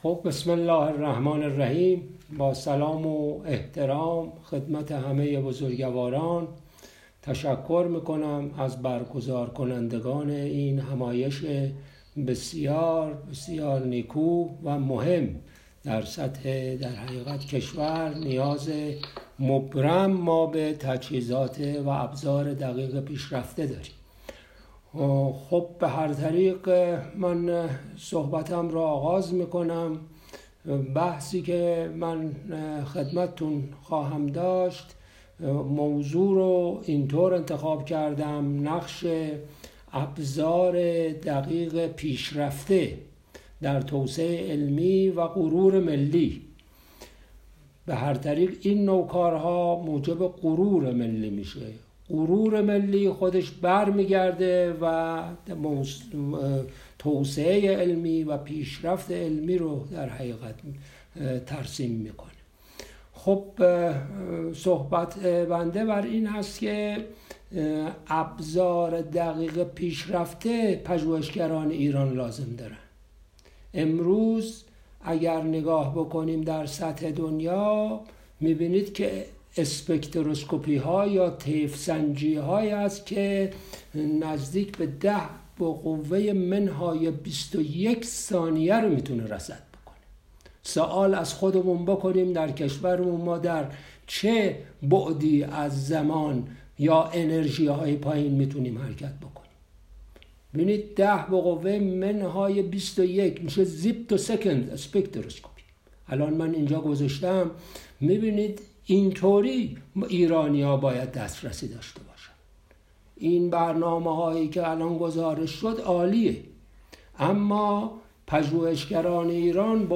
سخنرانی همایش ملی تجهیزات و فناوری های آزمایشگاهی